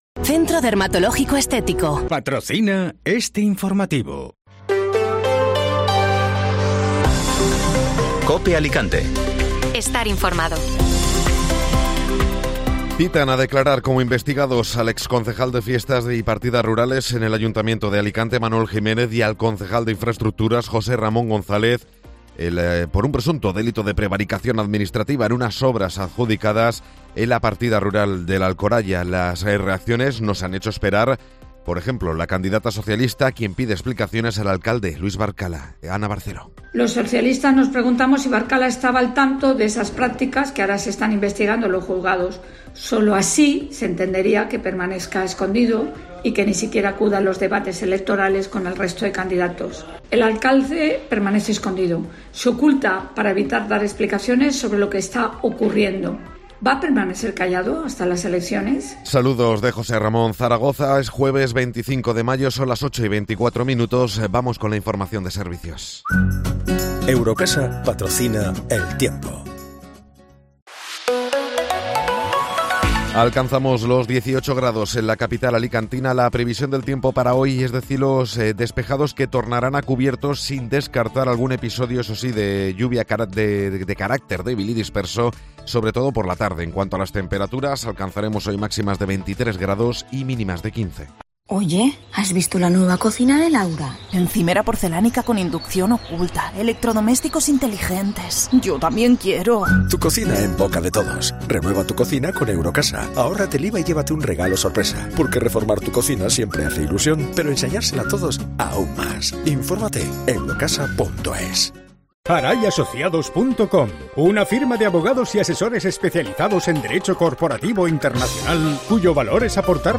Informativo Matinal (Jueves 25 de Mayo)